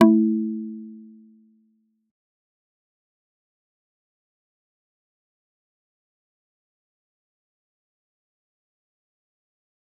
G_Kalimba-A3-mf.wav